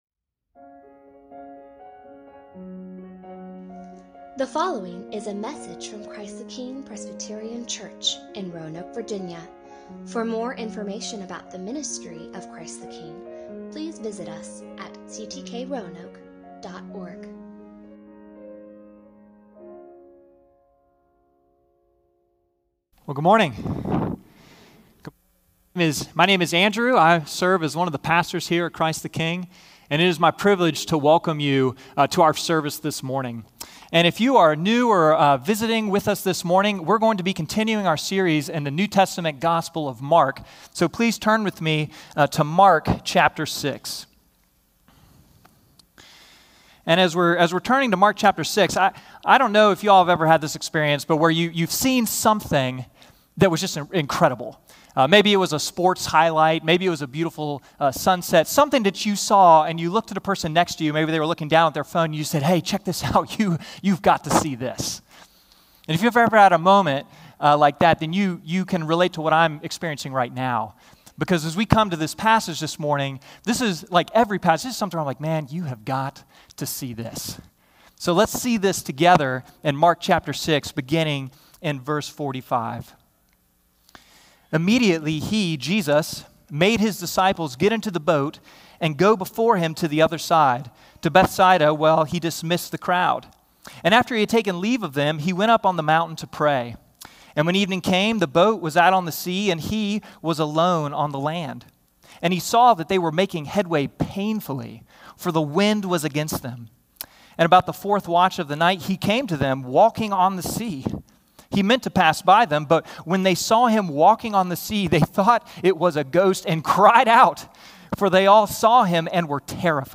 Sermon on Mark 6:45-54: You've Got to See This - Spring